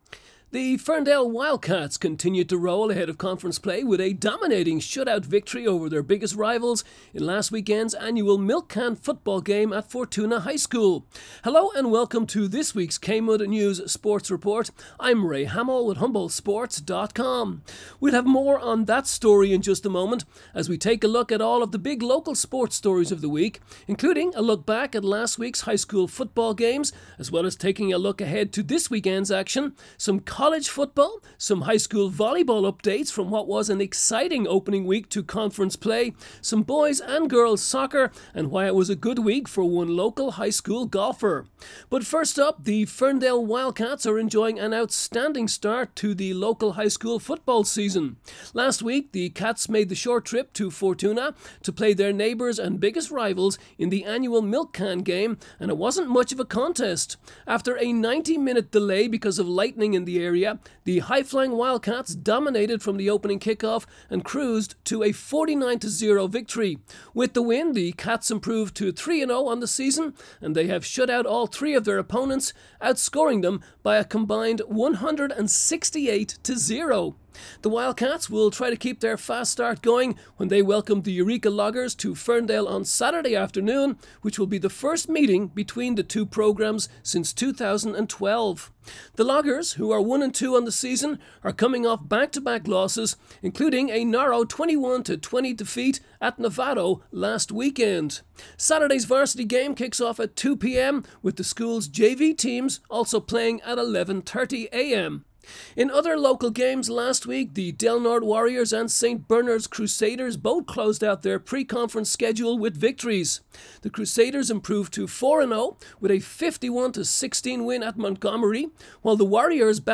KMUD News Sports Report Sept.25